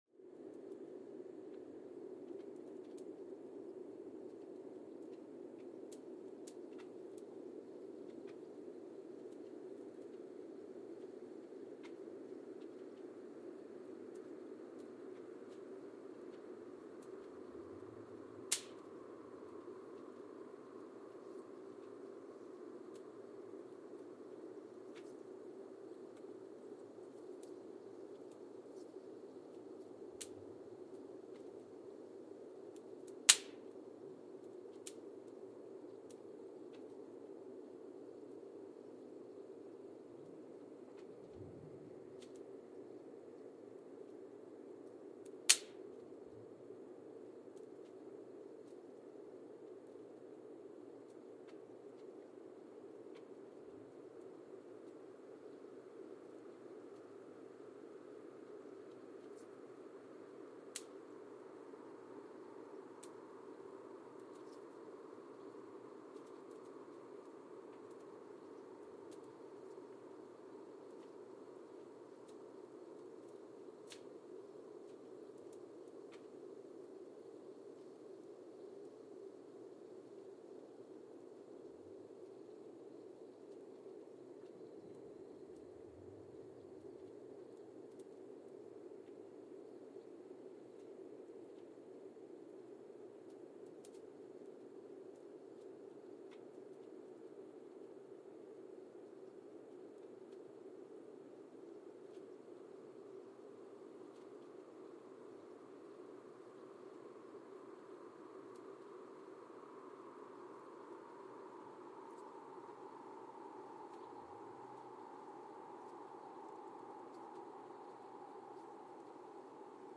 Mbarara, Uganda (seismic) archived on November 28, 2017
Sensor : Geotech KS54000 triaxial broadband borehole seismometer
Speedup : ×1,800 (transposed up about 11 octaves)
Loop duration (audio) : 05:36 (stereo)